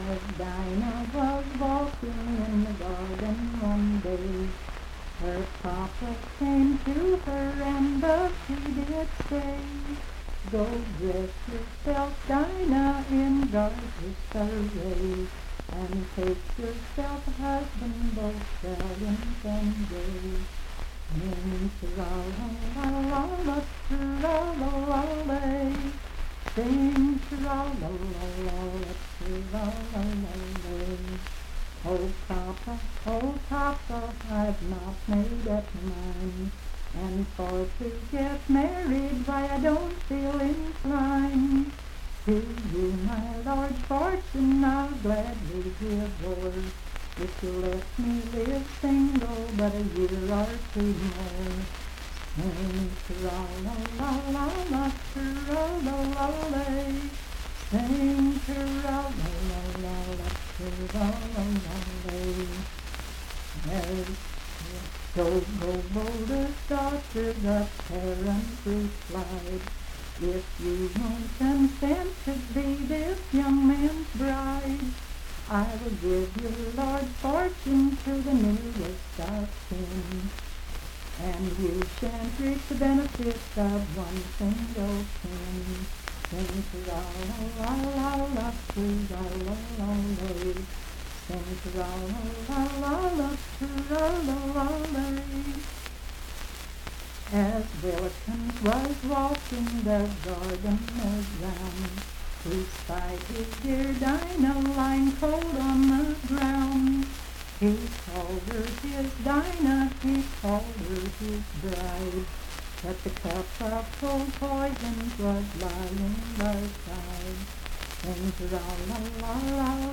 Unaccompanied vocal music
Verse-refrain 6(6w/R).
Voice (sung)